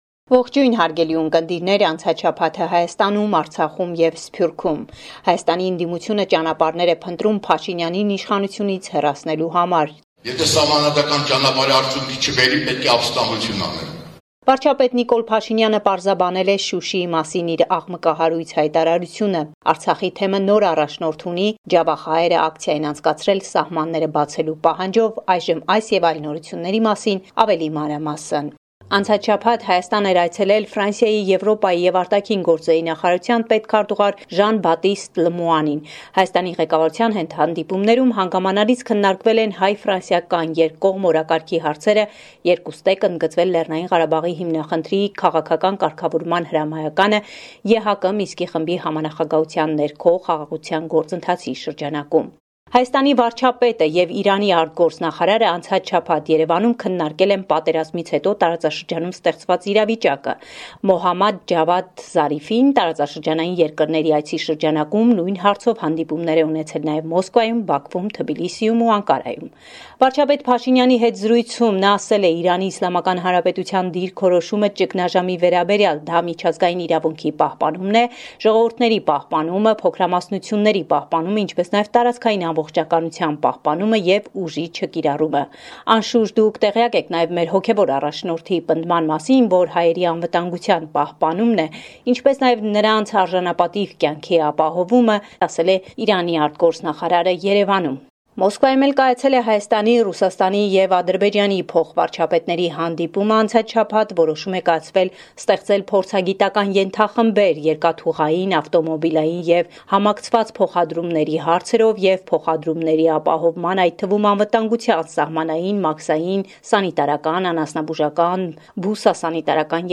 Latest News from Armenia – 2 February 2021